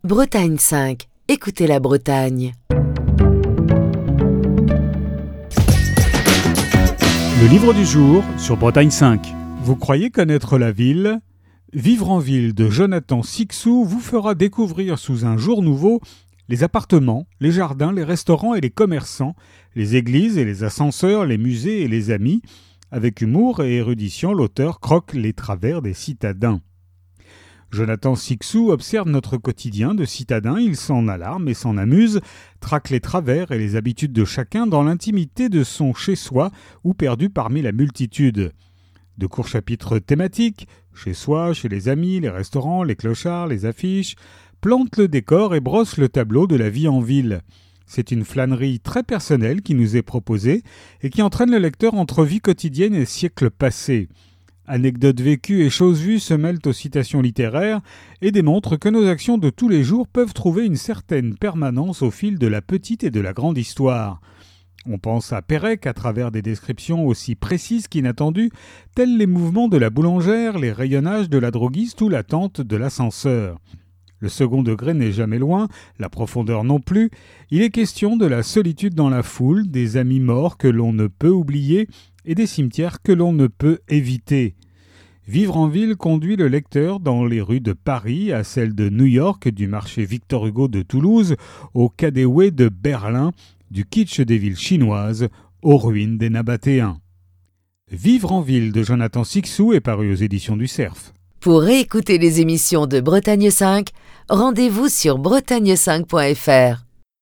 Chronique du 22 septembre 2023.